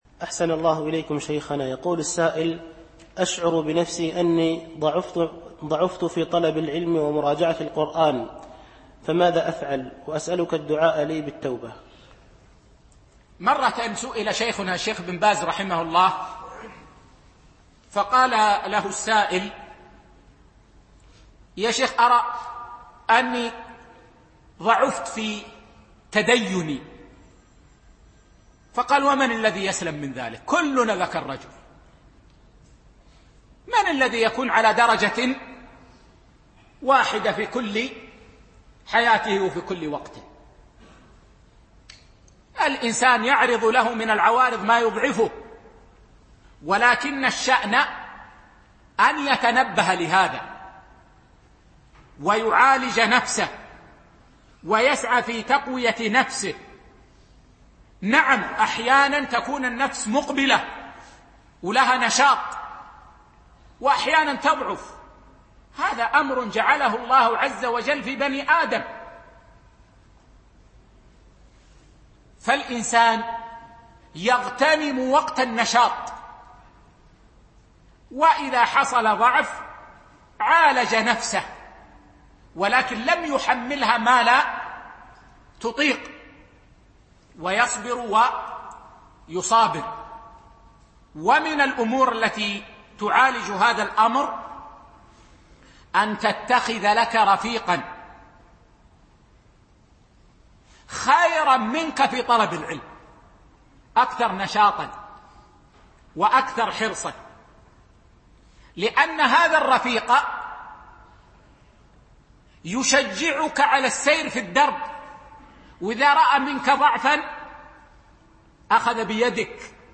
MP3 Mono 22kHz 32Kbps (VBR)